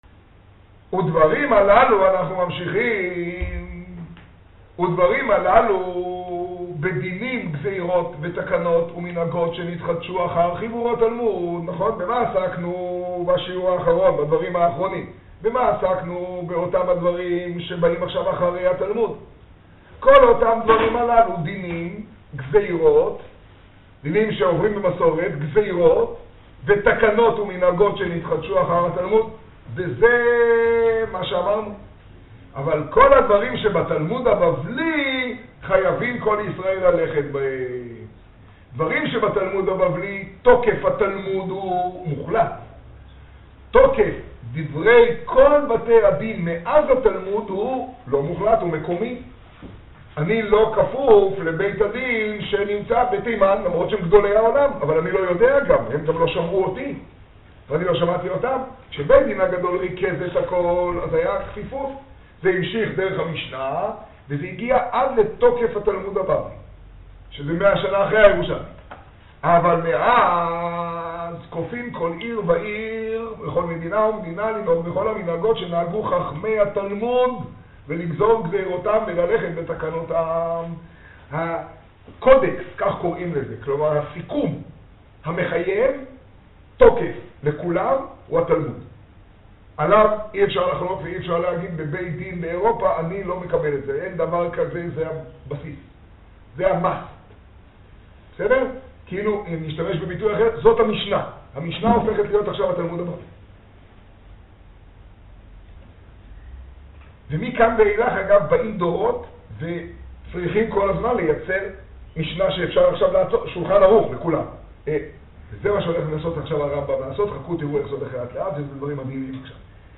השיעור במגדל, כה כסלו תשעה.